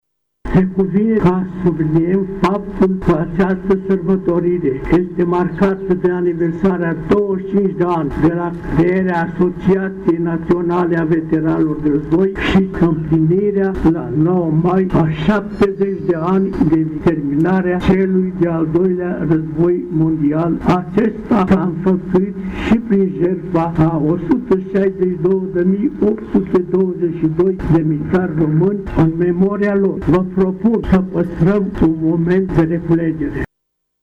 Astăzi, cu prilejul Zilei Veteranilor de Război, la Cimitirul Eroilor a avut loc o ceremonie de comemorare a eroilor români căzuți pe câmpurile de luptă.